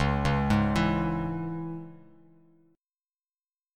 Listen to C#m strummed